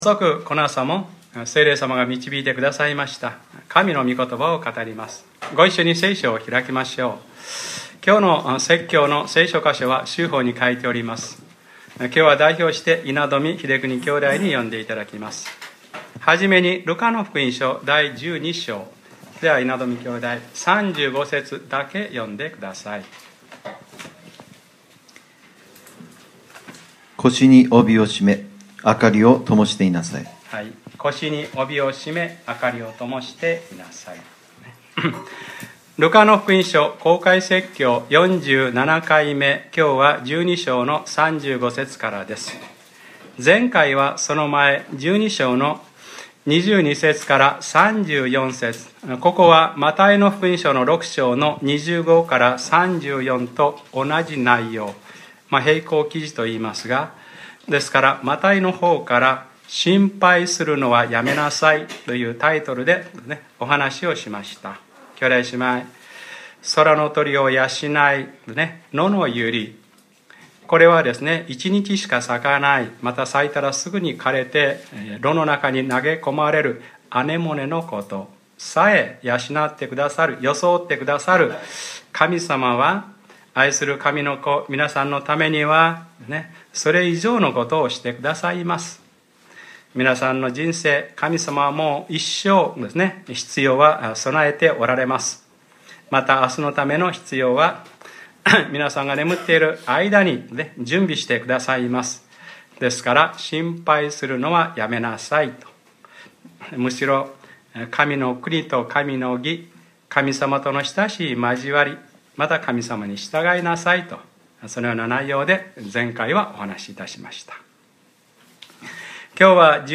2014年11月02日（日）礼拝説教 『ルカｰ４７：腰に帯を締め、明りをともしていなさい』 | クライストチャーチ久留米教会